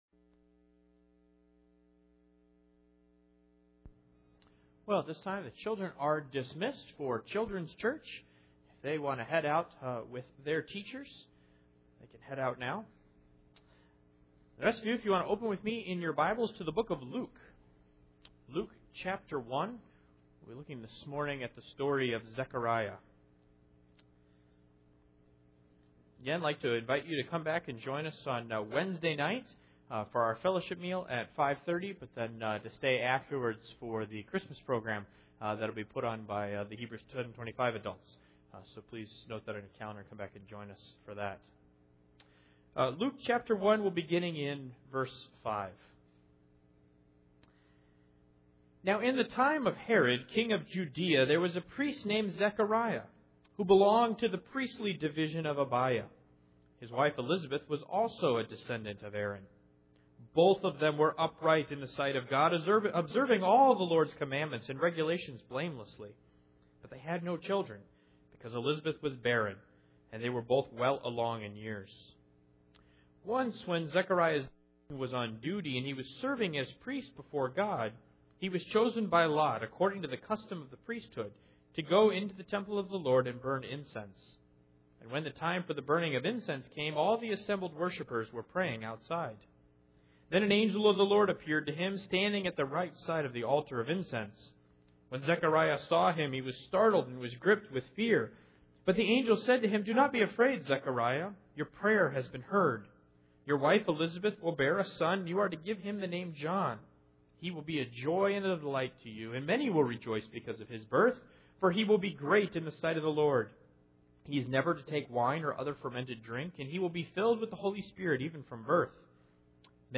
Luke 1:5-25 Service Type: Sunday Morning Zechariah perceives he has a problem